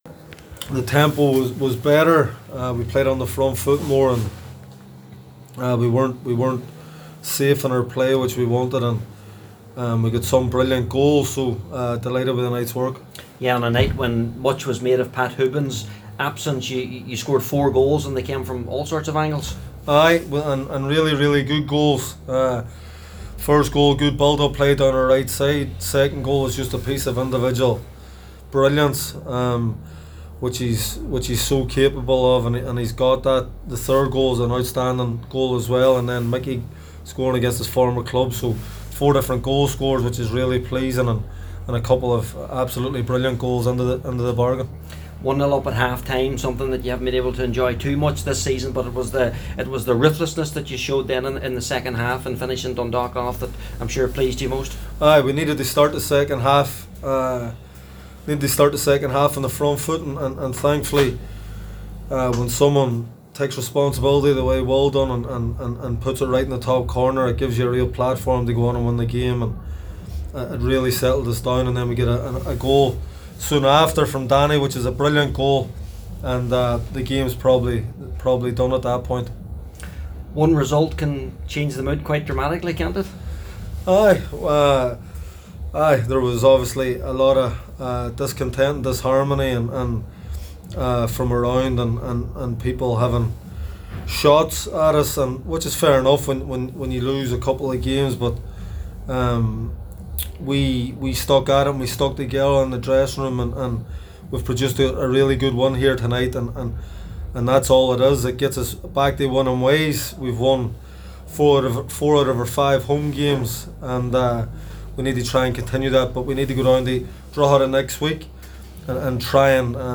After the game, Higgins gave his thoughts to the press…